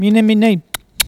Cri pour appeler le chat ( prononcer le cri )
Catégorie Locution